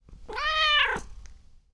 Cat Meowing
Category 🐾 Animals